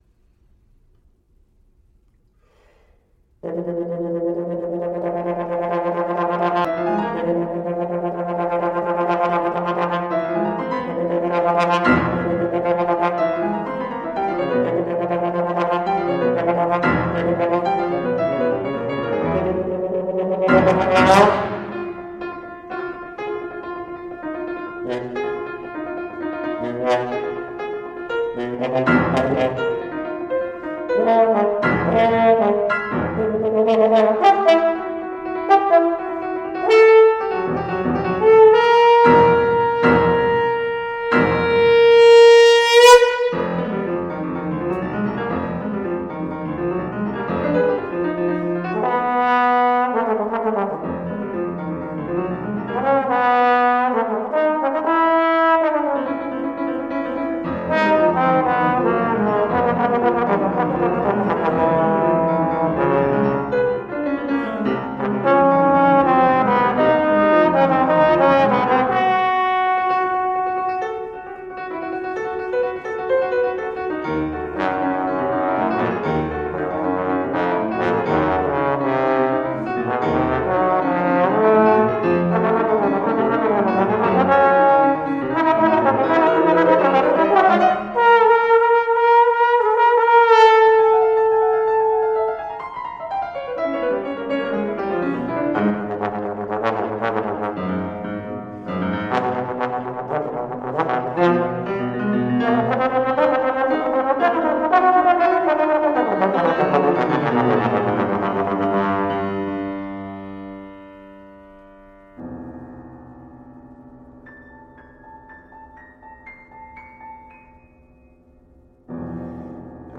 for trombone and piano.